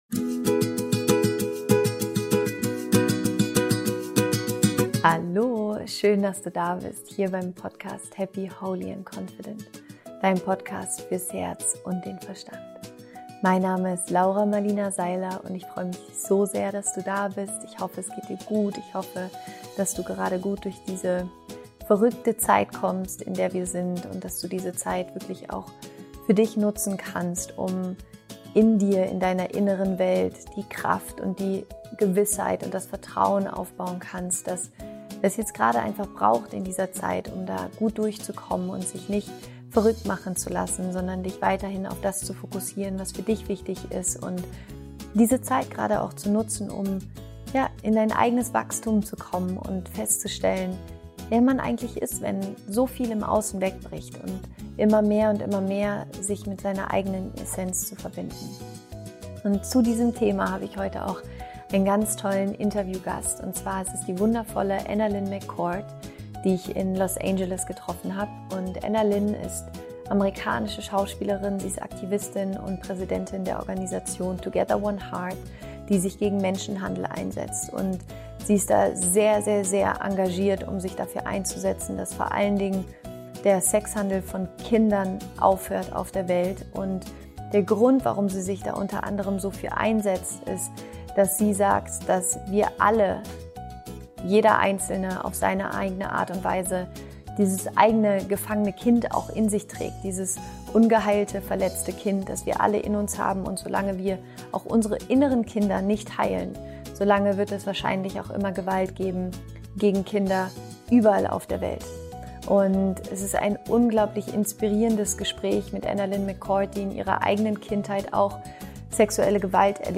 Eins der schönsten und bewegendsten Interviews, das ich für den Podcast geführt habe, ist das Gespräch mit AnnaLynne McCord. AnnaLynne ist eine amerikanische Schauspielerin, Aktivistin und Präsidentin der Organisation Together1Heart, die sich gegen Menschenhandel einsetzt.